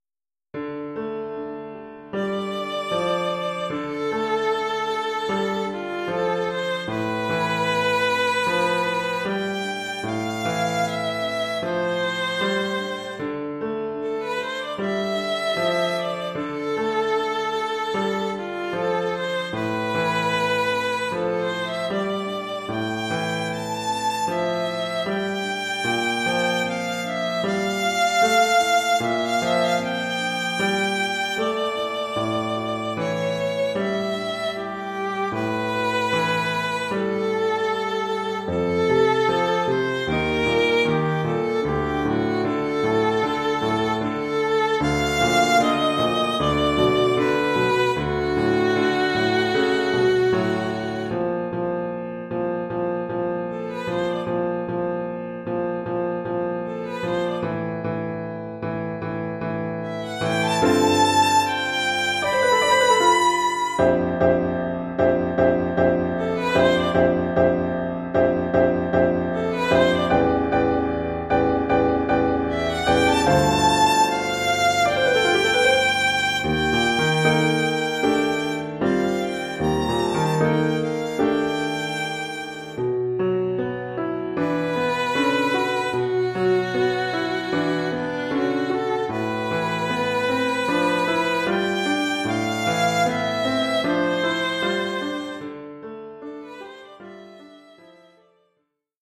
Formule instrumentale : Violon et piano
Oeuvre pour violon et piano.